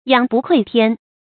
仰不愧天 注音： ㄧㄤˇ ㄅㄨˋ ㄎㄨㄟˋ ㄊㄧㄢ 讀音讀法： 意思解釋： 仰：抬頭；愧：慚愧。